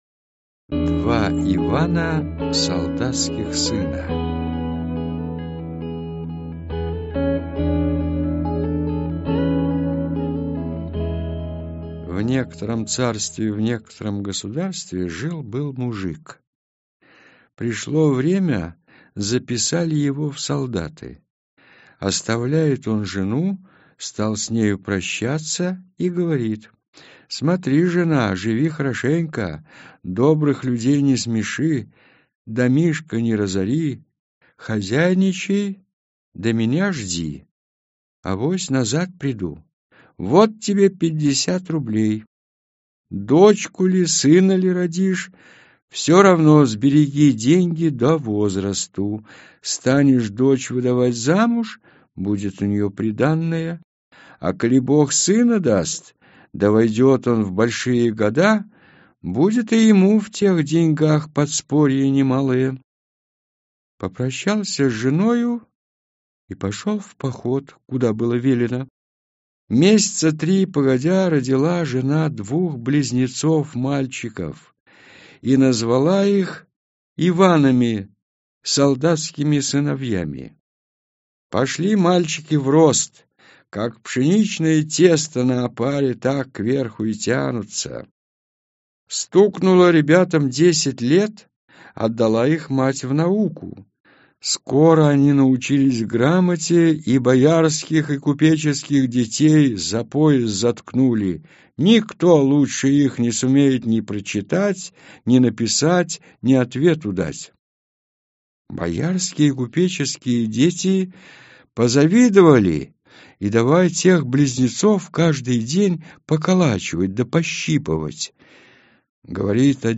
Аудиокнига Сказки о богатырской силе и доблести | Библиотека аудиокниг